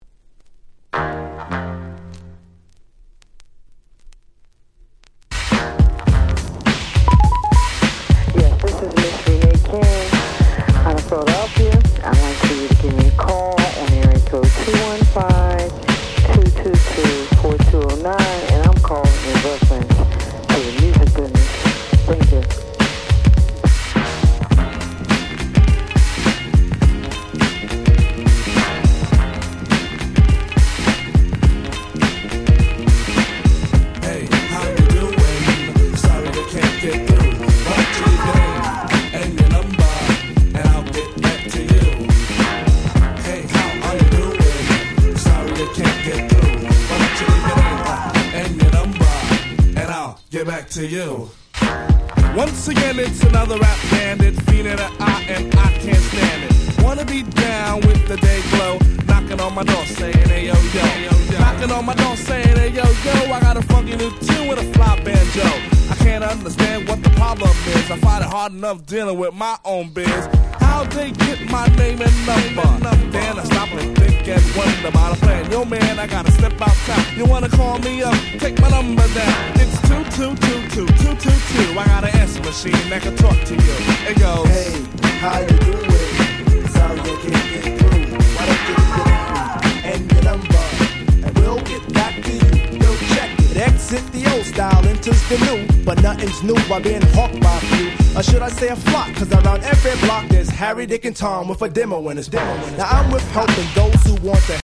91' Smash Hit Hip Hop !!
Boom Bap ブーンバップ